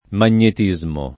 [ man’n’et &@ mo ]